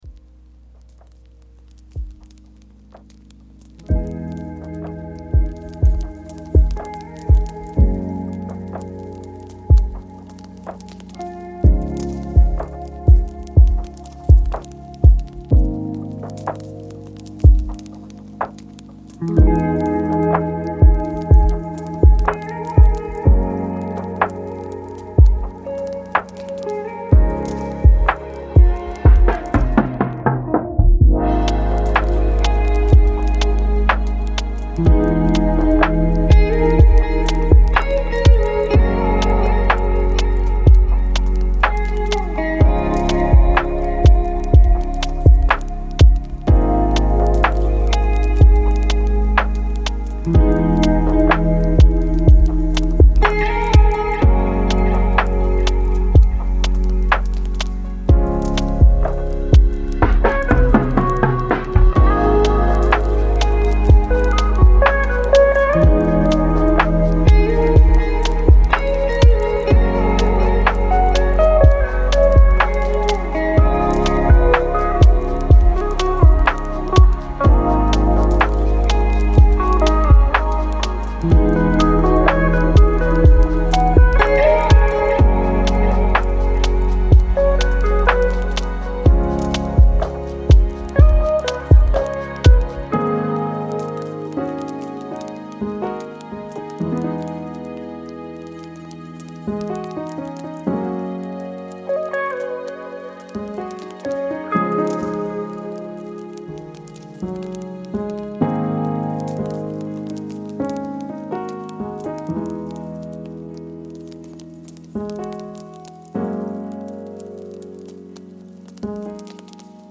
Gamma 40 Hz : Focus et Mémoire